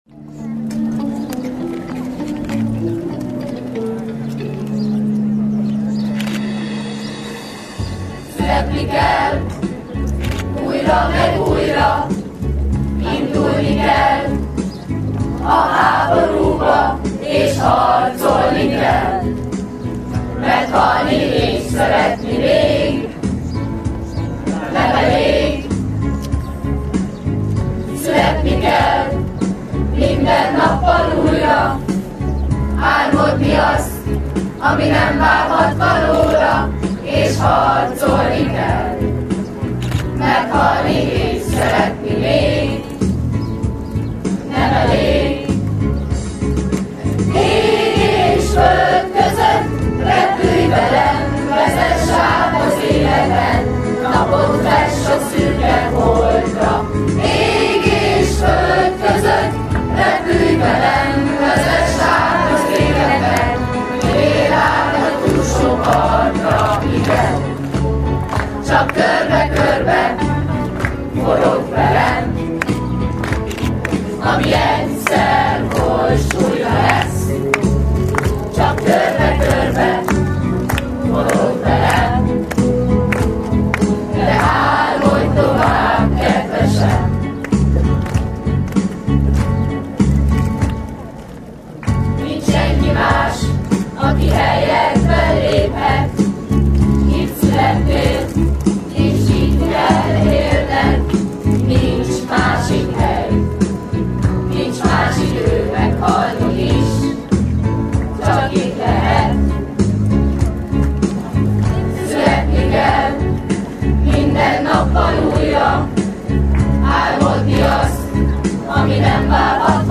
A végzős osztályok dallal búcsúztak.